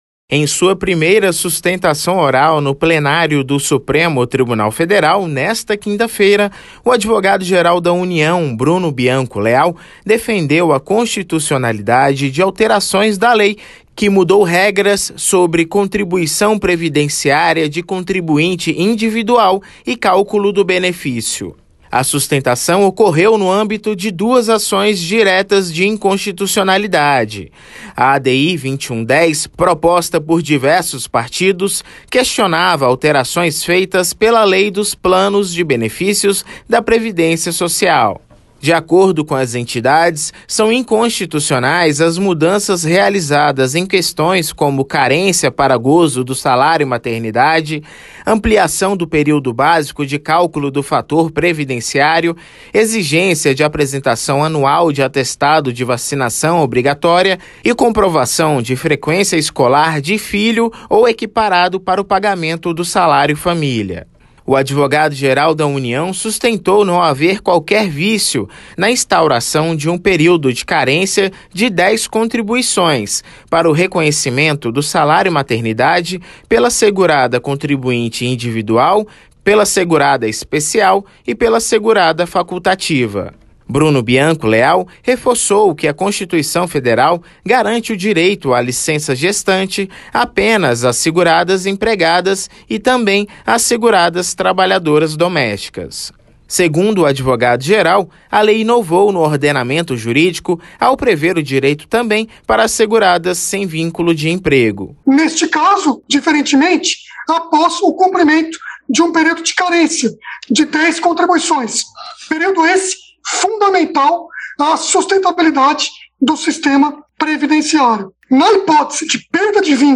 19-08 - Bruno Bianco Leal defende constitucionalidade de alterações previdenciárias em primeira sustentação oral no STF